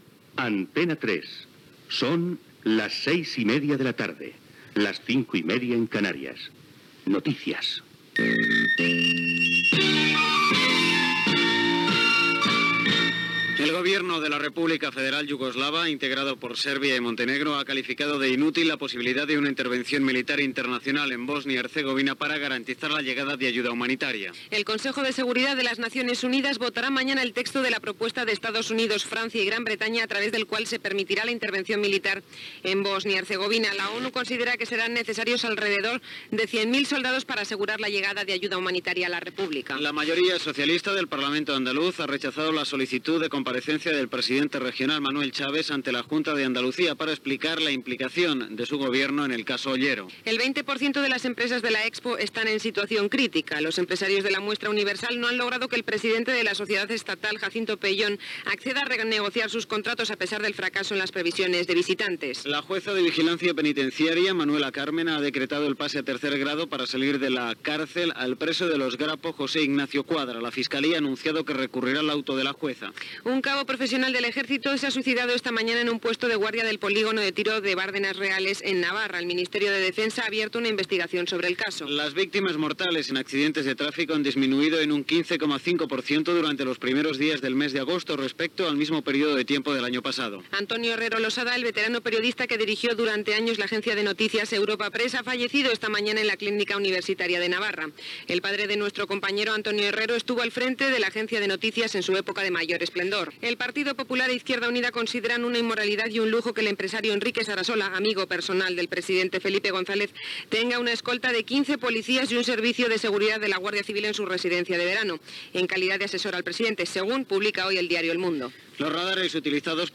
Hora, careta del butlletí de notícies, informacions de: Bòsnia, Andalusia, víctimes en accidents de trànsit, etc. Promoció de "Los conciertos en Antena 3". Indicatiu de "Viva el verano", secció "La canción del verano": cançons que es poden votar en la segona fase i quatre trucades telefòniques.
Informatiu
FM